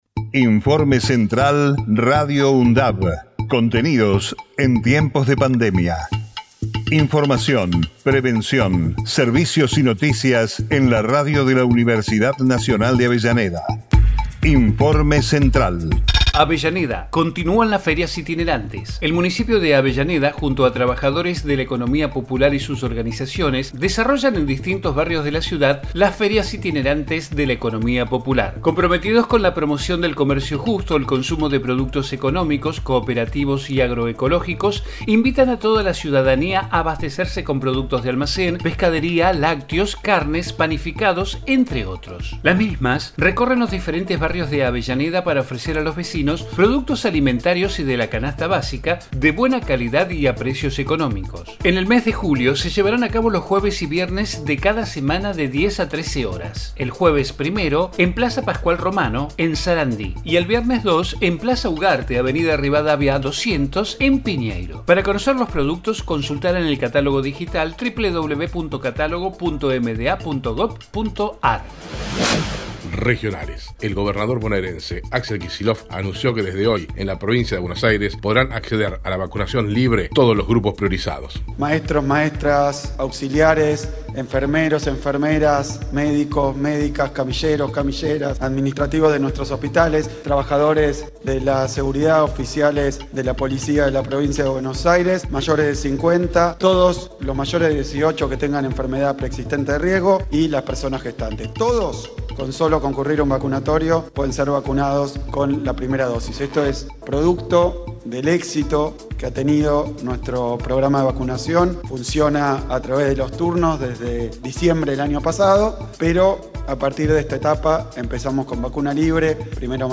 COVID-19 Informativo en emergencia 30 de JUNIO 2021 Texto de la nota: Informativo Radio UNDAV, contenidos en tiempos de pandemia. Información, prevención, servicios y noticias locales, regionales y universitarias.